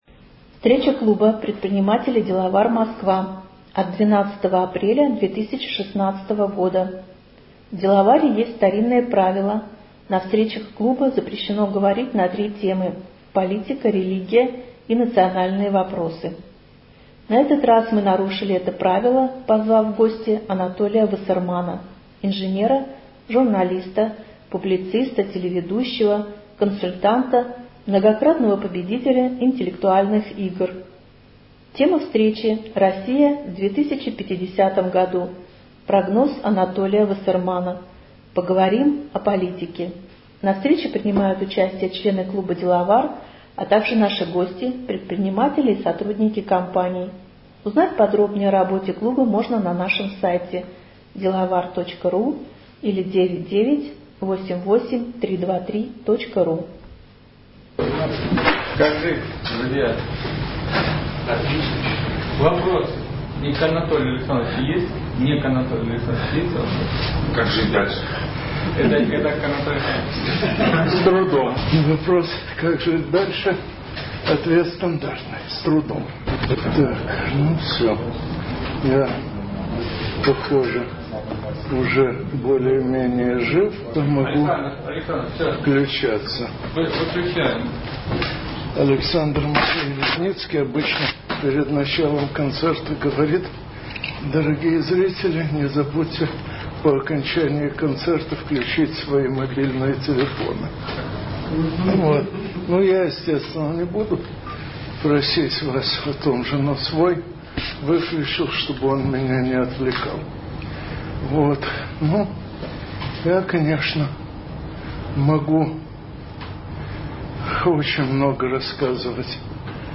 Анатолий рассказал о том, что нас ждёт впереди и ответил на вопросы участников встречи.